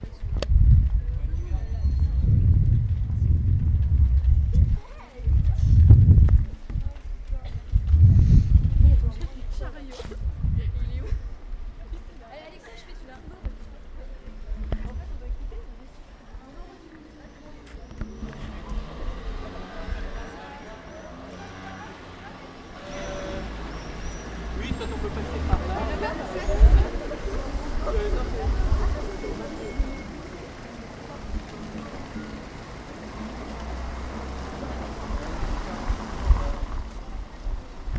Limoges: Place de la Motte
Oiseaux, passants, moto, klaxon